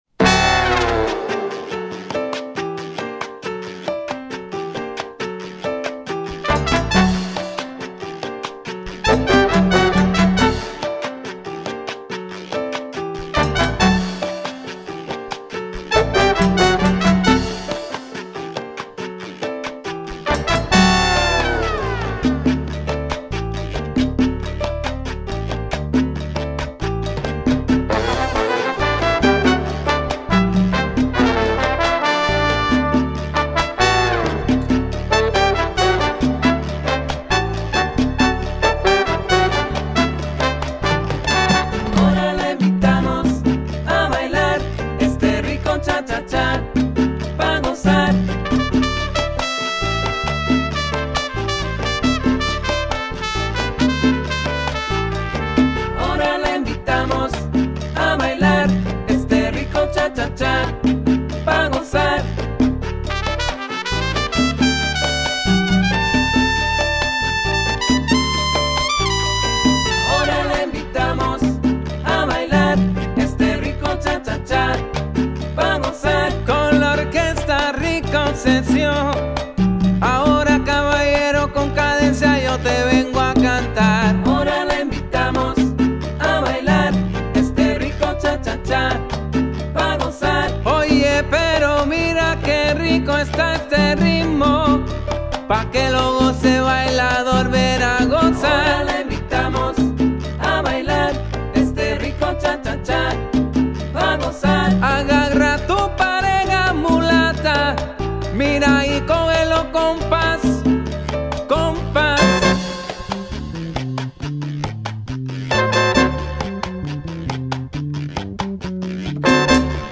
Congas/Vocals and Timbales/Musical Director
Salsa